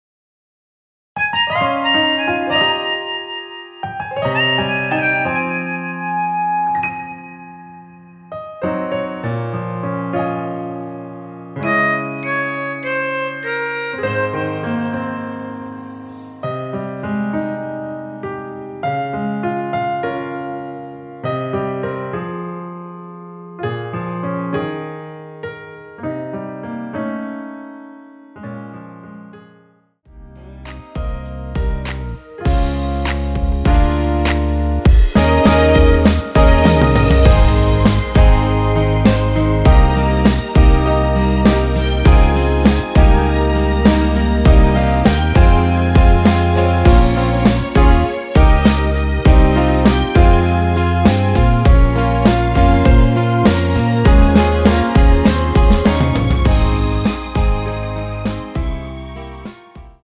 원키에서(+1)올린 MR입니다.
Ab
◈ 곡명 옆 (-1)은 반음 내림, (+1)은 반음 올림 입니다.
앞부분30초, 뒷부분30초씩 편집해서 올려 드리고 있습니다.